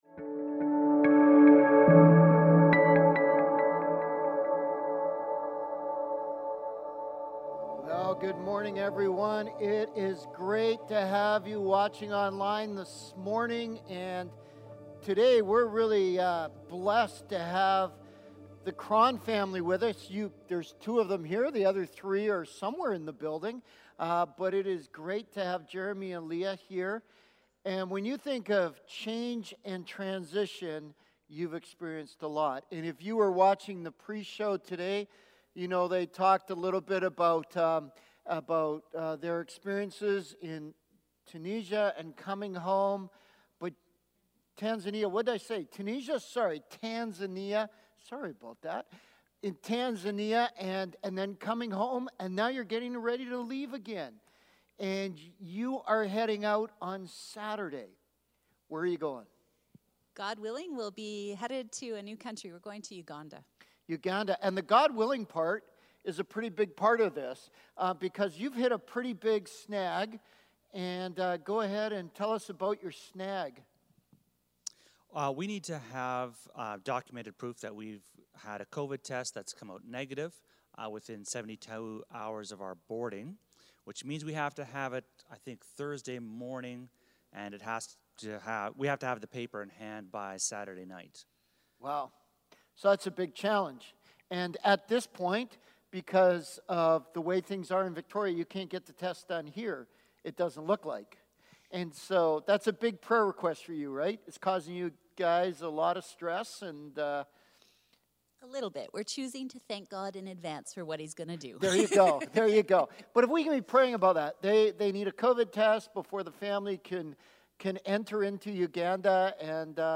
Today's sermon is the third in our fall sermon series. Our hope is that during this time of frustration and hurt, that we will turn to God with our grief and use the Psalms as tools for lament.